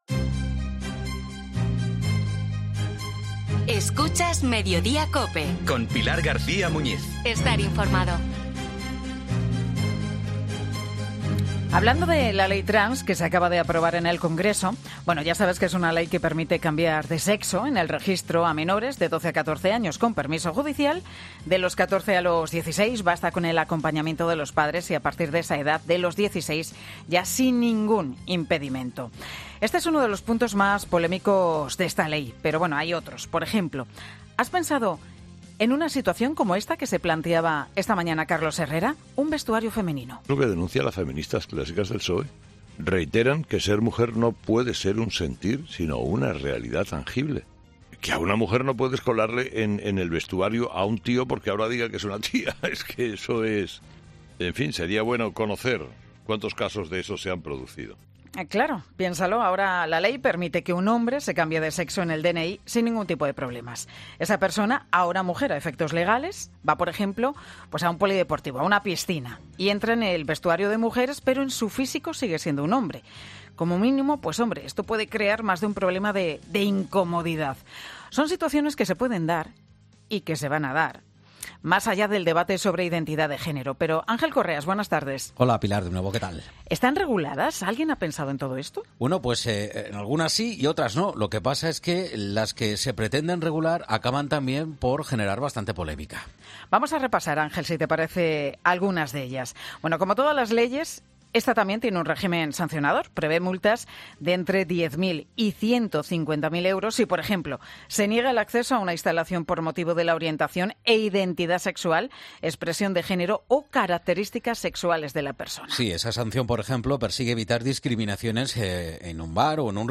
abogada en derecho deportivo en 'Mediodía COPE'